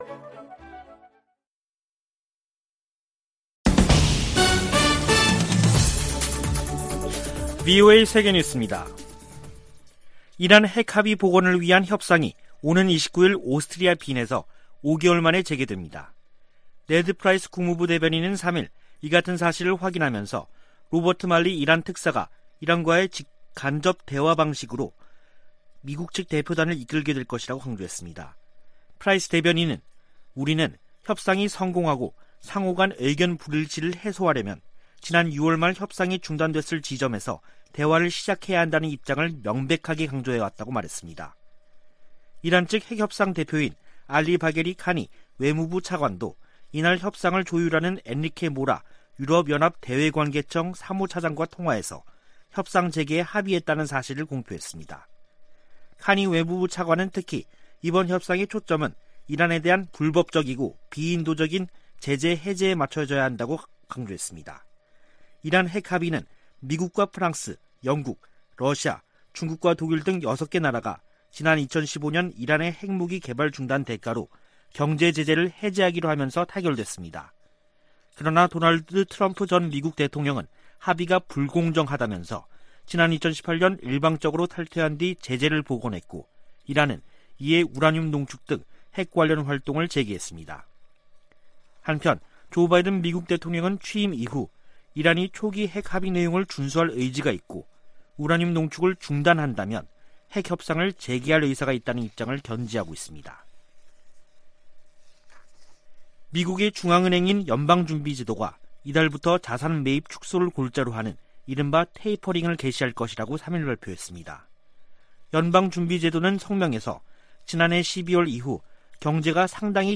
VOA 한국어 간판 뉴스 프로그램 '뉴스 투데이', 2021년 11월 4일 2부 방송입니다. 북한이 유엔총회에서 주한 유엔군사령부 해체를 다시 주장했습니다. 마크 밀리 미 합참의장은 북한 정부가 안정적이라며 우발적 사건이 발생하지 않을 것으로 내다봤습니다. 중국의 핵탄두가 2030년 1천개를 넘어설 수 있다고 미 국방부가 전망했습니다.